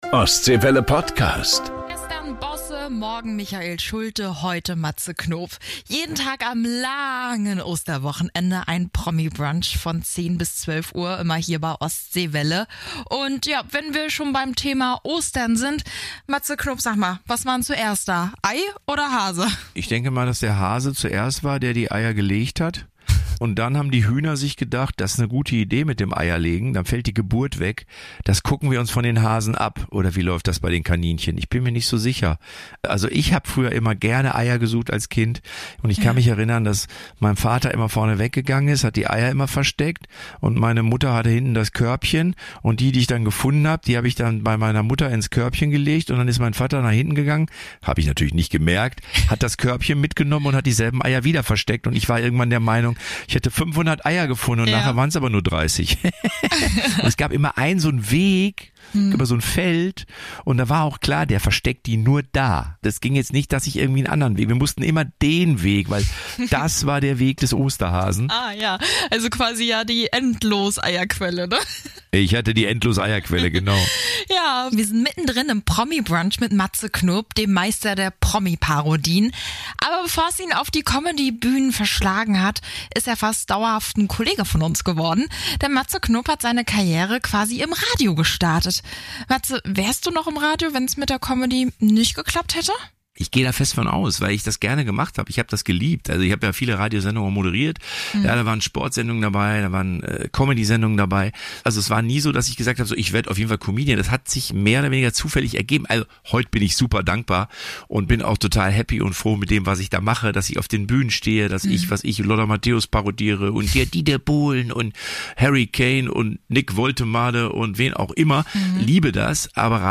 MATZE KNOP spricht am Ostseewelle-Mikrofon über Macken, Promis und seine Comedy-Programme. Hier erfahrt ihr auch mehr ganz private Momente.